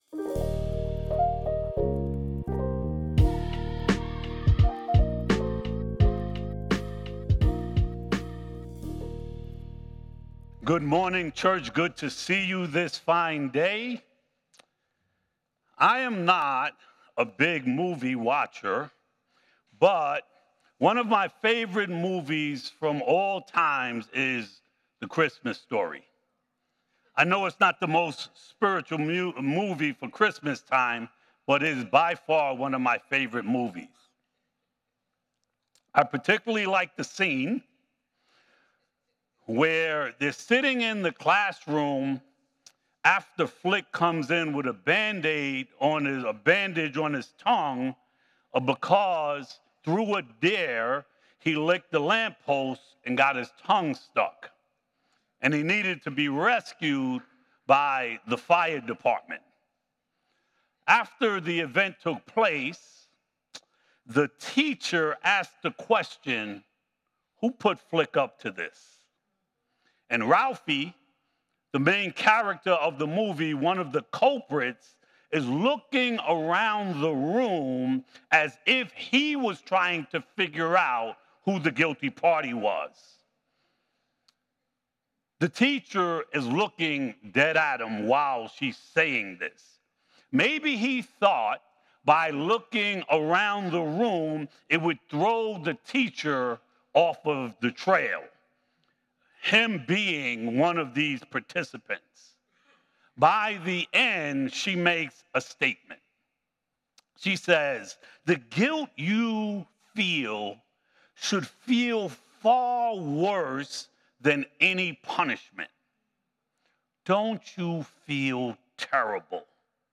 Sermons from Light of the World Church in Minisink Hills, PA